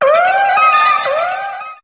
Cri de Luminéon dans Pokémon HOME.